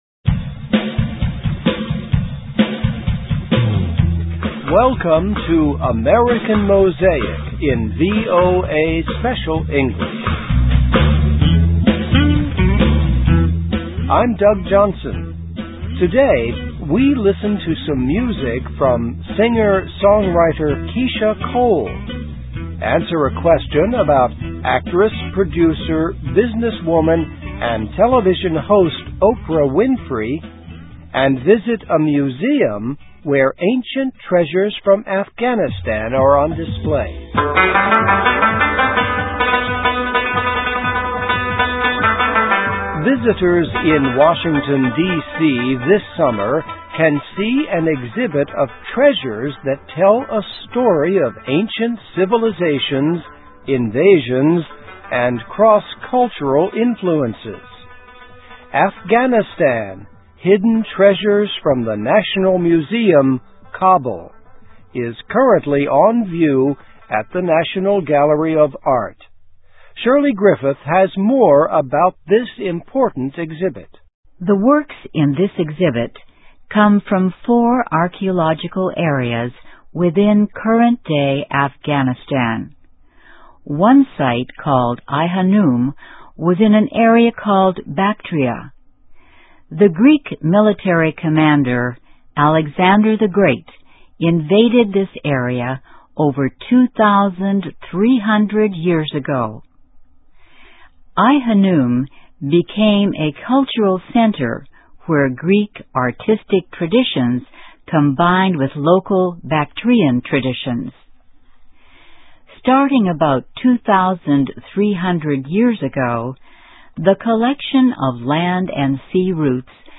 Listen and Read Along - Text with Audio - For ESL Students - For Learning English